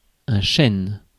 Ääntäminen
Ääntäminen France: IPA: [œ̃ ʃɛn] Tuntematon aksentti: IPA: /ʃɛn/ Haettu sana löytyi näillä lähdekielillä: ranska Käännös Konteksti Substantiivit 1. дъб {m} kasvi Suku: m .